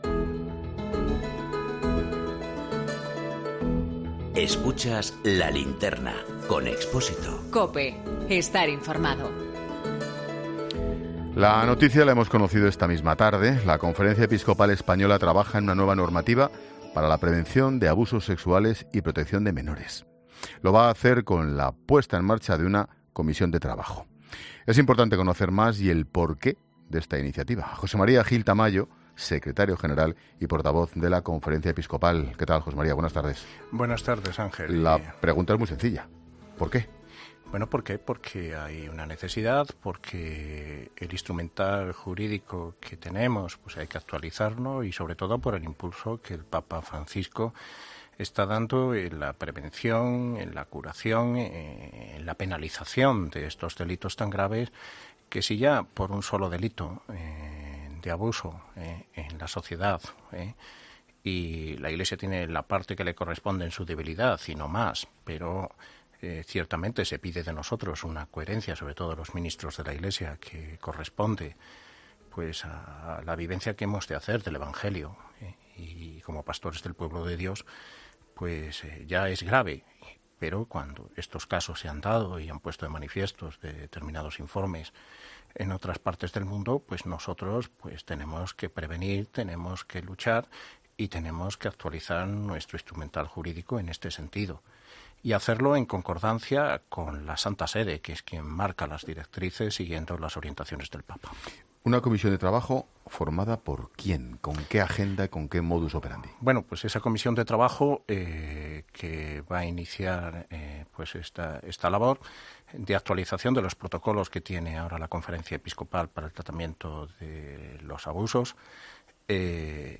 El secretario general y portavoz de la Conferencia Episcopal Española, José María Gil Tamayo, ha explicado en 'La Linterna' que esta normativa se incorpora porque hay una necesidad.